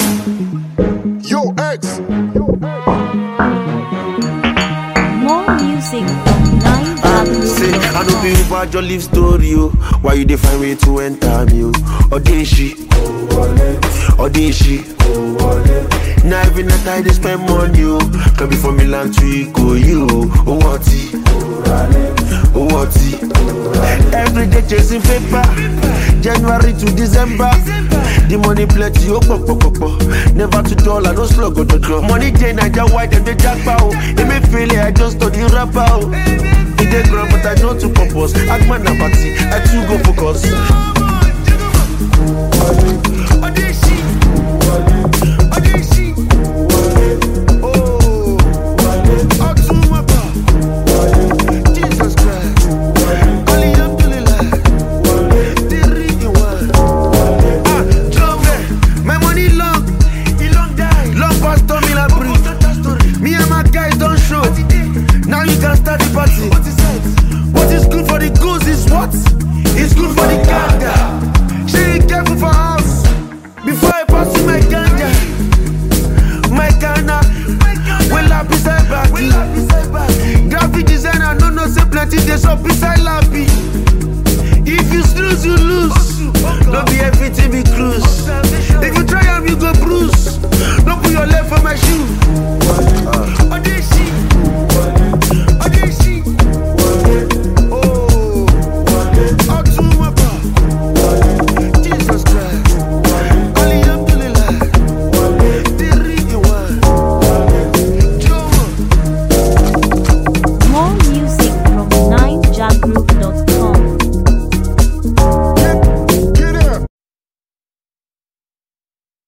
Noteworthy Nigerian rapper